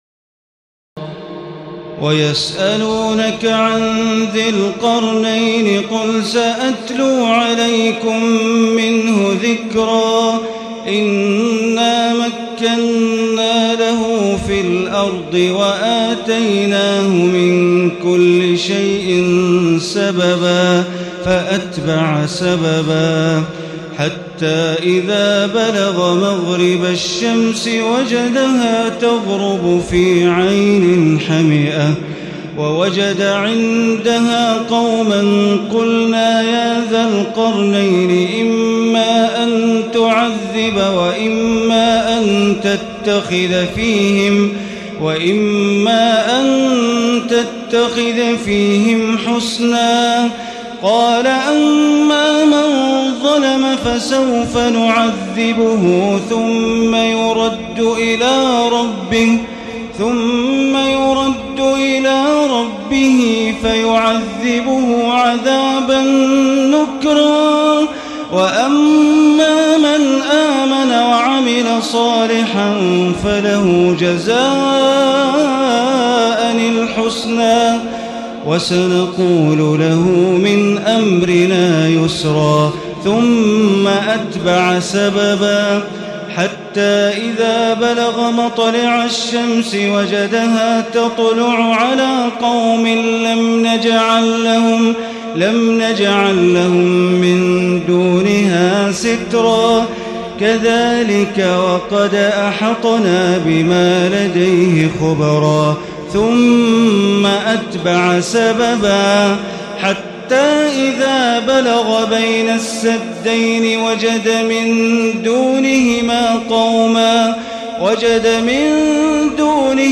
تراويح الليلة الخامسة عشر رمضان 1436هـ من سورتي الكهف (83-110) و مريم كاملة Taraweeh 15 st night Ramadan 1436H from Surah Al-Kahf and Maryam > تراويح الحرم المكي عام 1436 🕋 > التراويح - تلاوات الحرمين